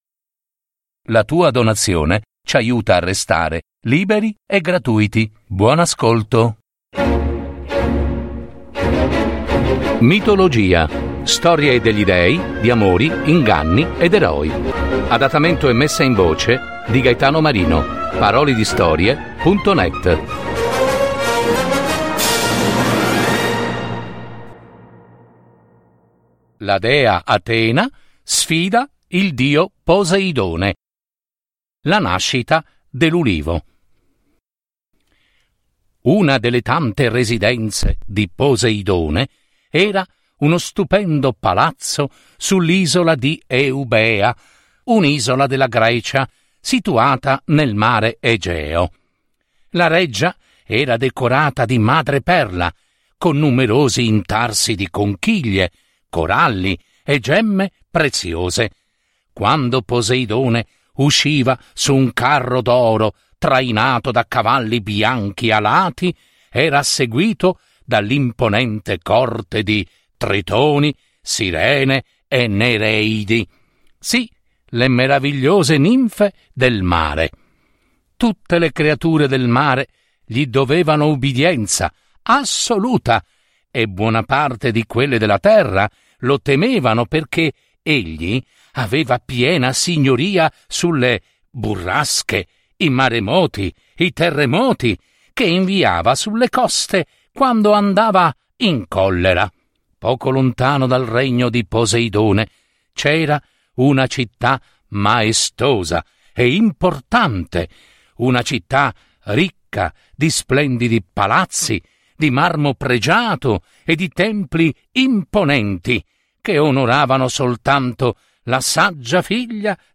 Adattamento e messa in voce